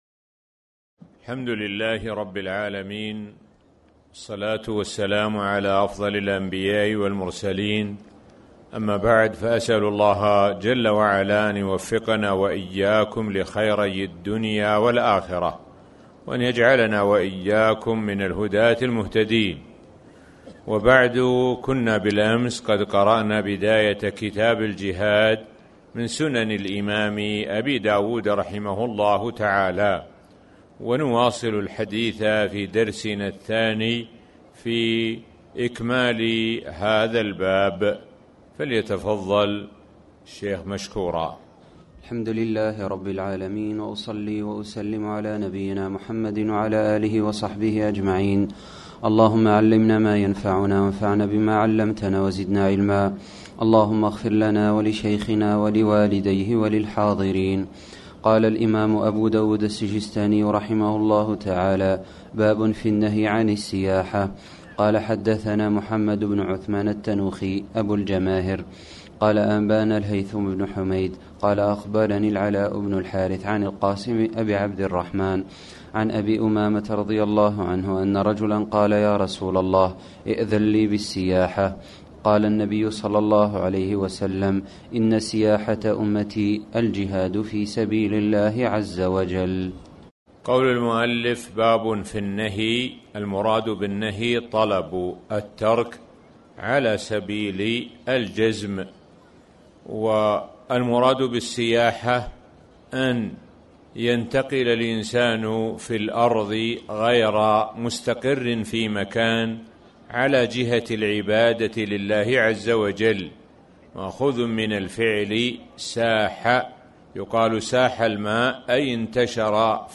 تاريخ النشر ٢ رمضان ١٤٣٩ هـ المكان: المسجد الحرام الشيخ: معالي الشيخ د. سعد بن ناصر الشثري معالي الشيخ د. سعد بن ناصر الشثري باب النهي عن السياحة